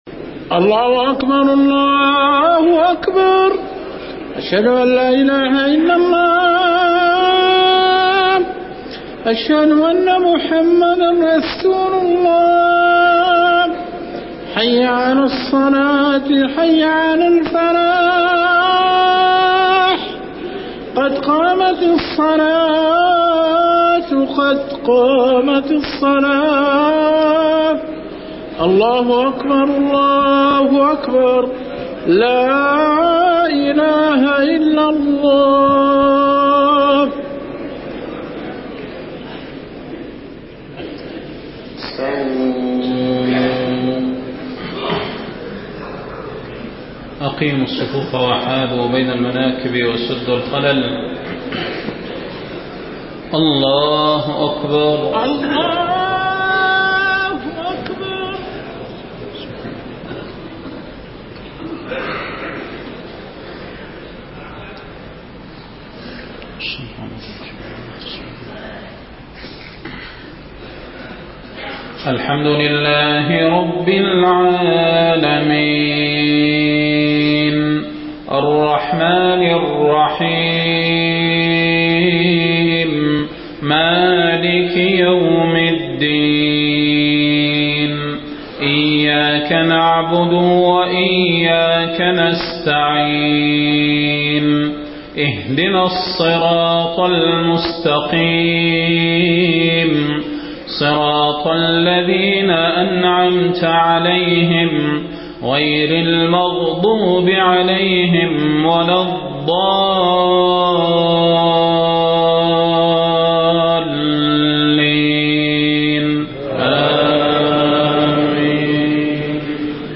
صلاة المغرب 8 محرم 1430هـ سورتي التين و العصر > 1430 🕌 > الفروض - تلاوات الحرمين